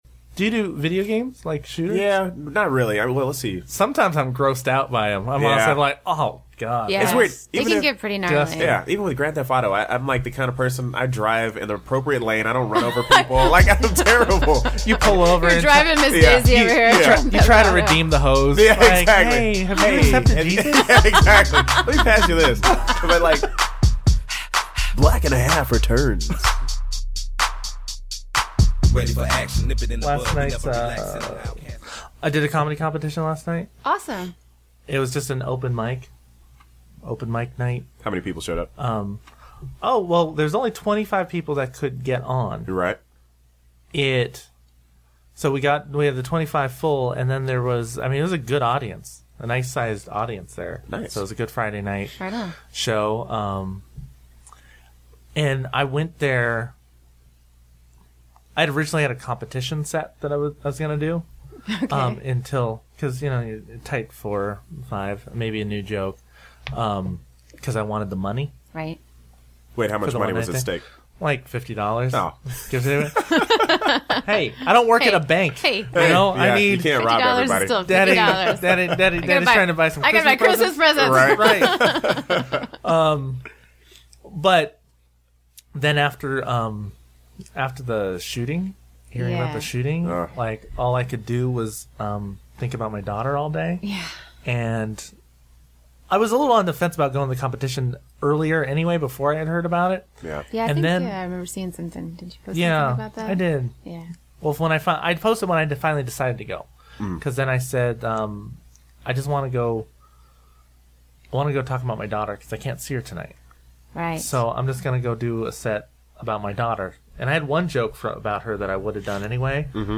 This episode was also taped the day after the Newtown tragedy and we did take some time to speak on that.